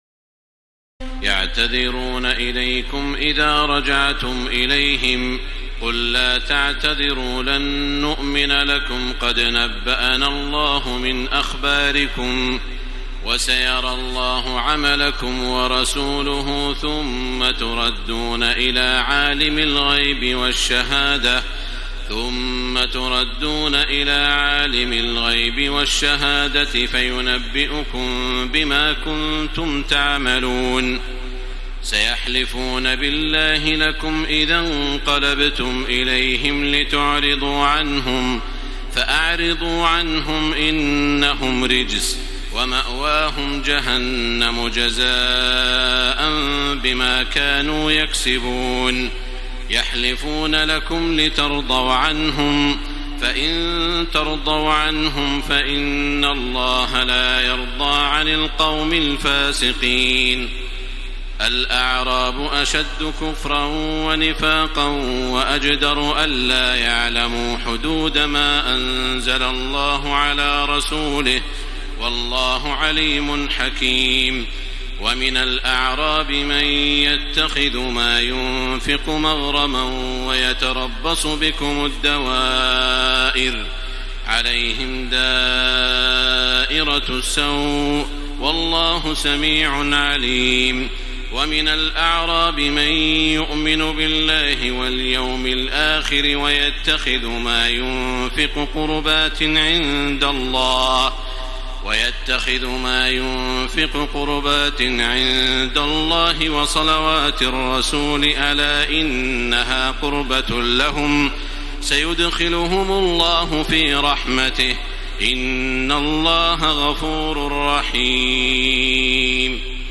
تراويح الليلة العاشرة رمضان 1434هـ من سورتي التوبة (94-129) و يونس (1-25) Taraweeh 10 st night Ramadan 1434H from Surah At-Tawba and Yunus > تراويح الحرم المكي عام 1434 🕋 > التراويح - تلاوات الحرمين